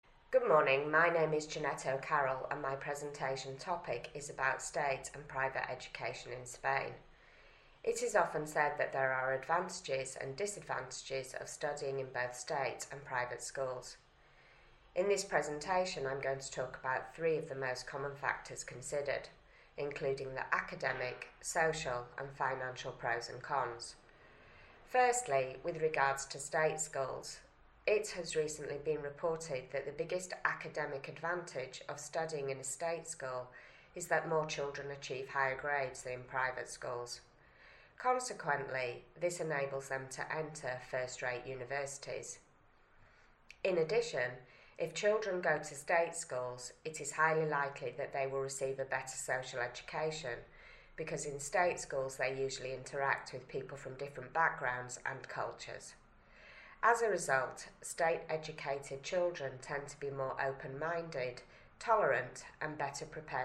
1: This model presentation responds to the following EOI B2 exam question: